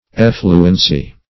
effluency - definition of effluency - synonyms, pronunciation, spelling from Free Dictionary Search Result for " effluency" : The Collaborative International Dictionary of English v.0.48: Effluency \Ef"flu*en*cy\, n. Effluence.
effluency.mp3